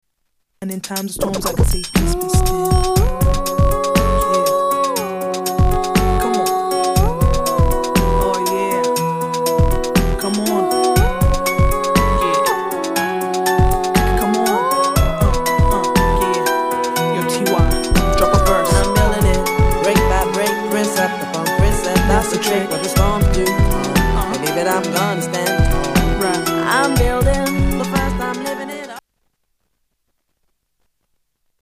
STYLE: R&B